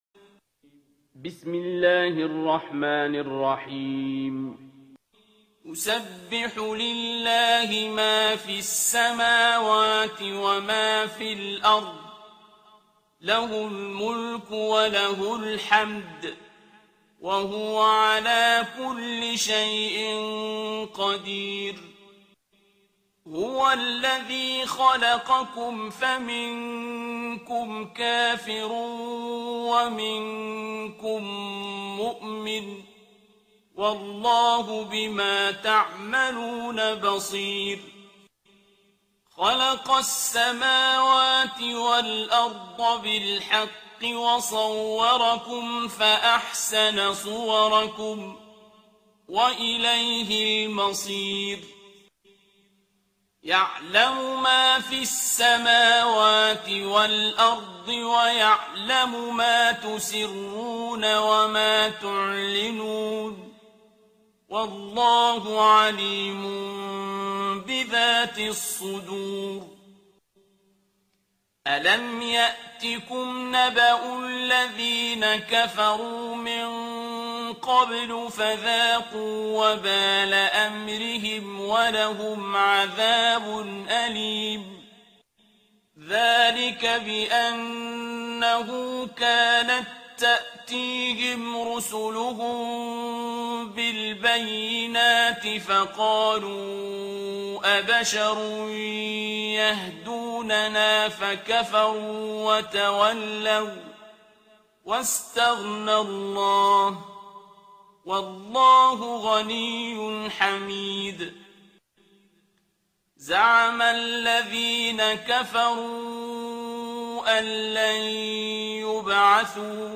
ترتیل سوره تغابن با صدای عبدالباسط عبدالصمد
064-Abdul-Basit-Surah-Al-At-Taghabun.mp3